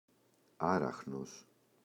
άραχνος [‘araxnos] – ΔΠΗ